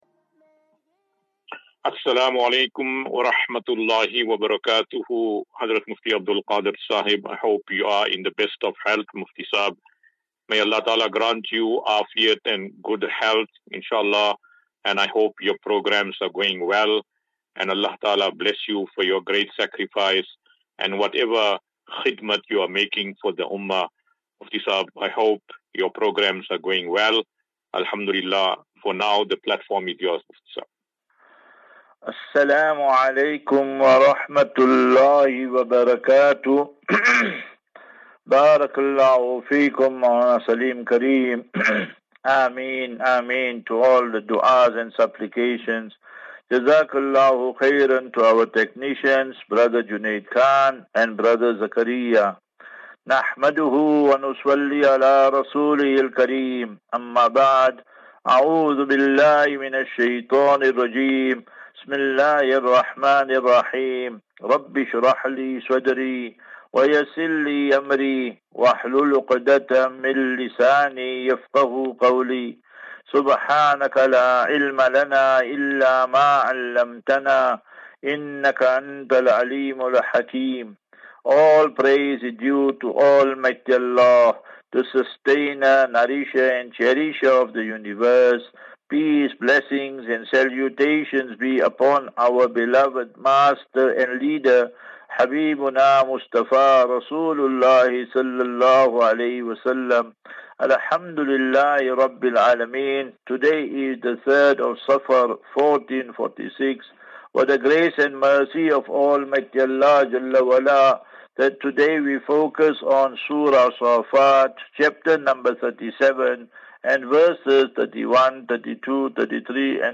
As Safinatu Ilal Jannah Naseeha and Q and A 8 Aug 08 August 2024.